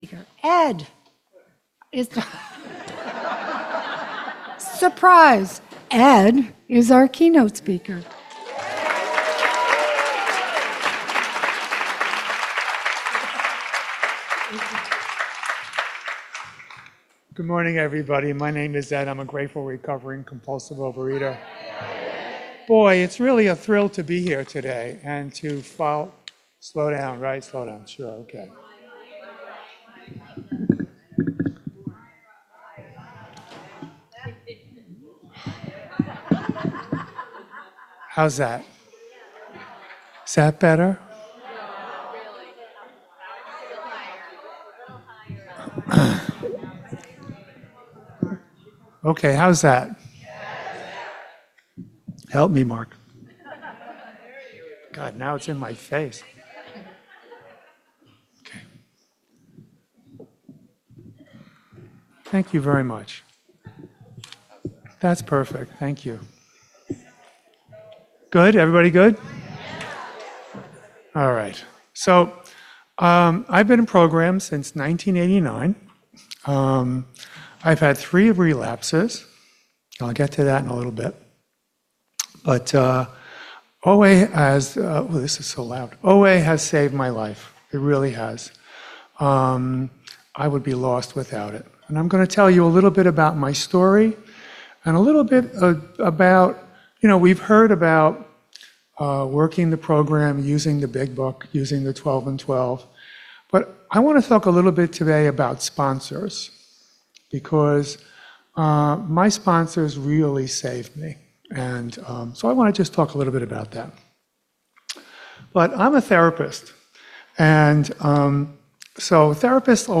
A keynote address given on Sunday at the 2024 OA Region 6 convention, held in October in Nashua, NH, US.